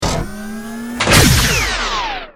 battlesuit_hugelaser.ogg